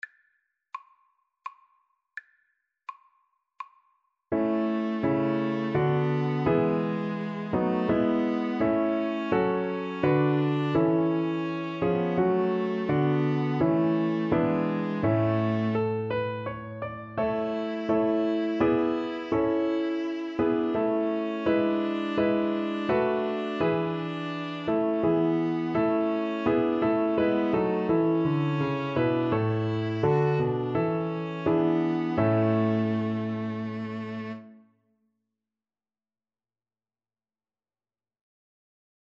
3/4 (View more 3/4 Music)
Piano Trio  (View more Easy Piano Trio Music)